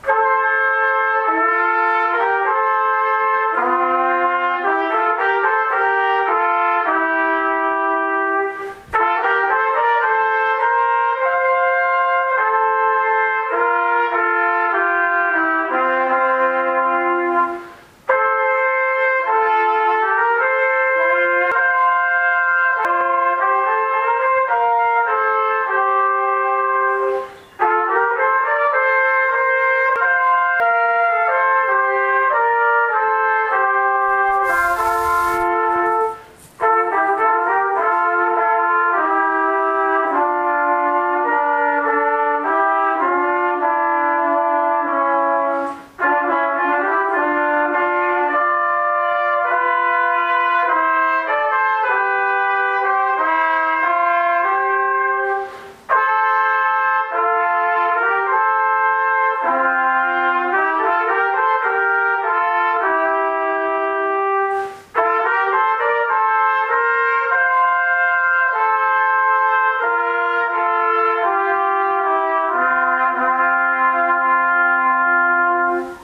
Un cantique très connu joué à la trompette !